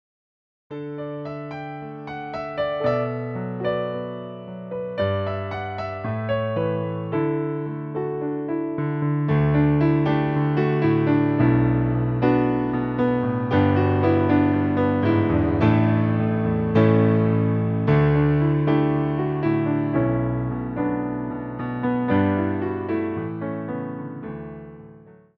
Wersja demonstracyjna:
56 BPM
D – dur